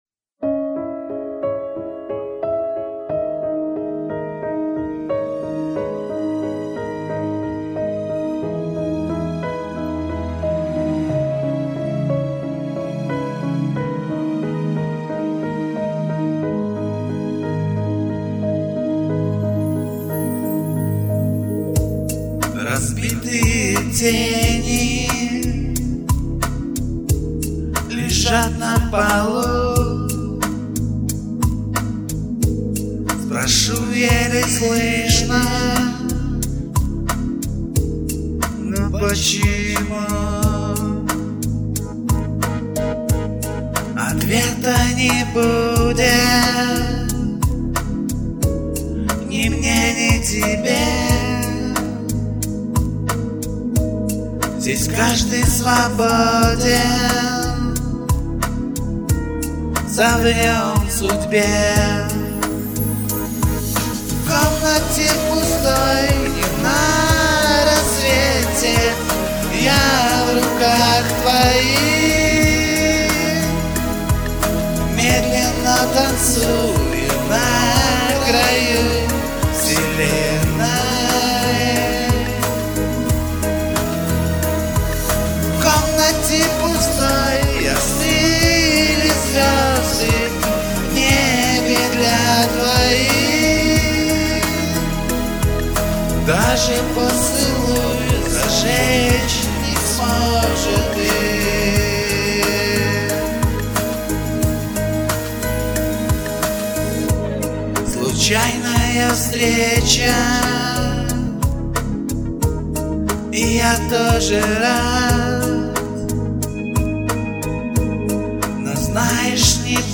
Голос красивенный и завораживающий !!!